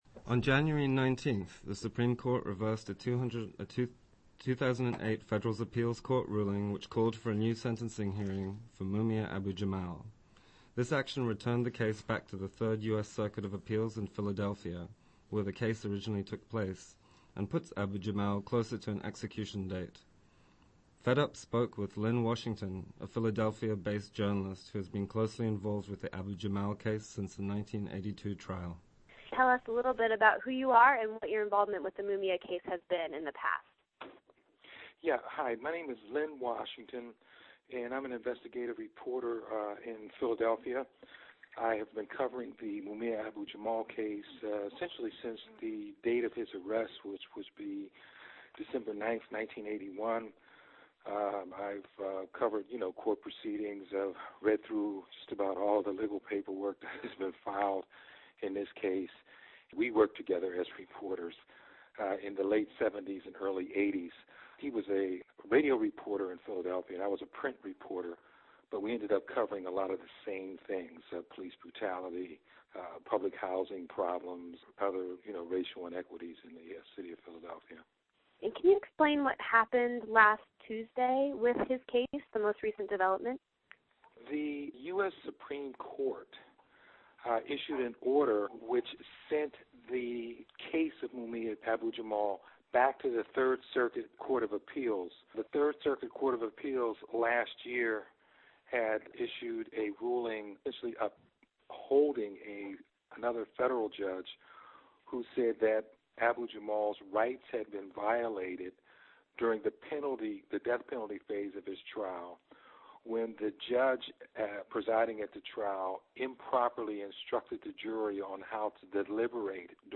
The sixteen minute segment of the Rustbelt Radio is included here.